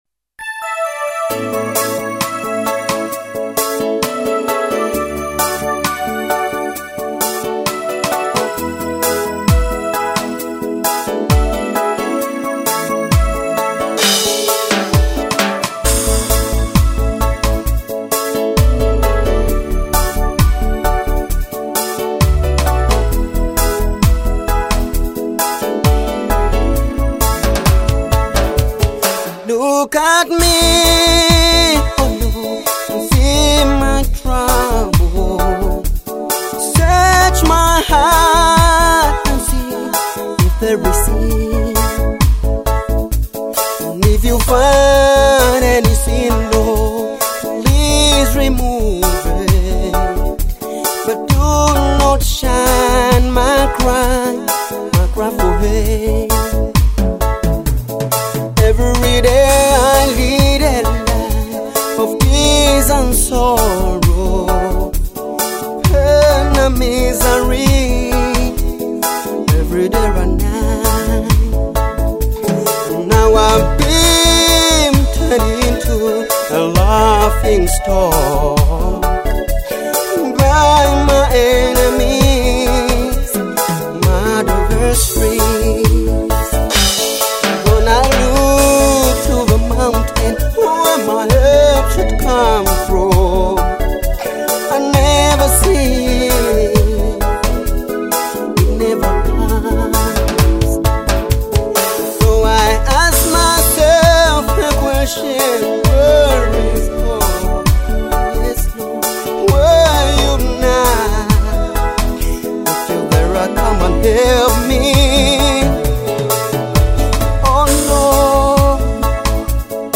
gospel
soulful vocals
create a heartfelt atmosphere